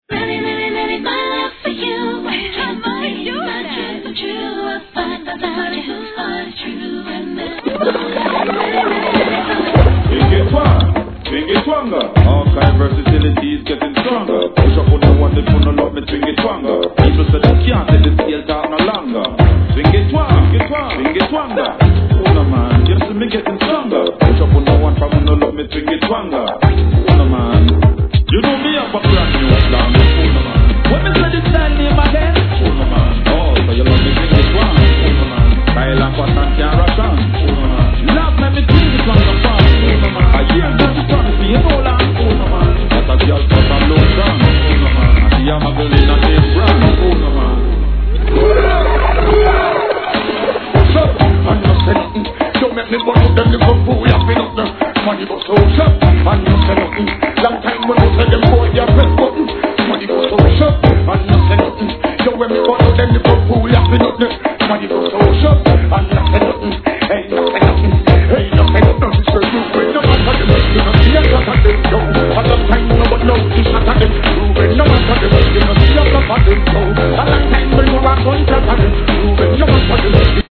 REGGAE X HIP HOP, R&BのMUSH UP物!